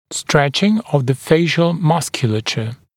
[‘streʧɪŋ əv ðə ‘feɪʃl ‘mʌskjuləʧə][‘стрэчин ов зэ ‘фэйшл ‘маскйулэчэ]растяжение лицевой мускулатуры